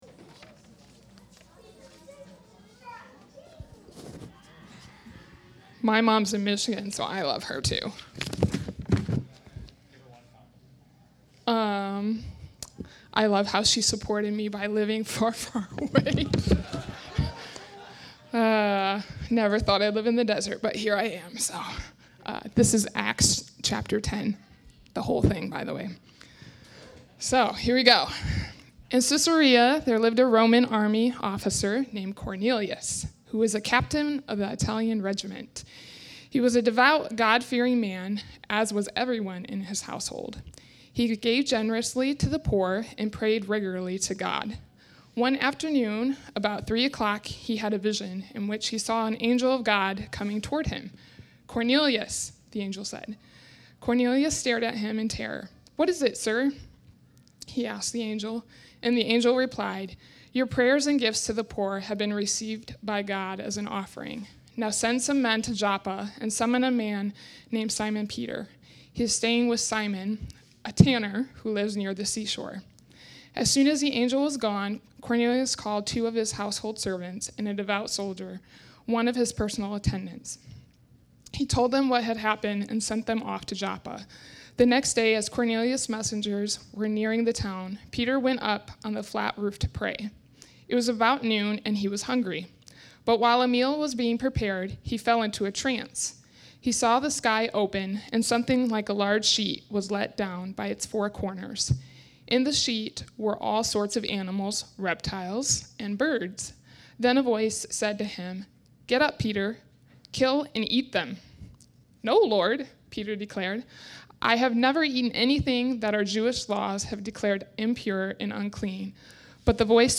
Mission Church is a small and relationally minded Christian community in the center of Tucson Arizona. Each teaching is based out of a Scripture passage and most are from within a study of an entire biblical book.